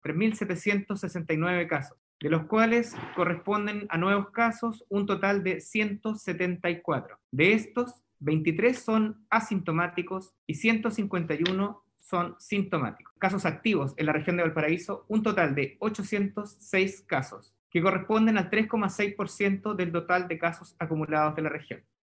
Las cifras fueron confirmadas por el seremi (s) de Salud, quien sostuvo que los casos activos son 806, que corresponden al 3,6 de los contagios acumulados.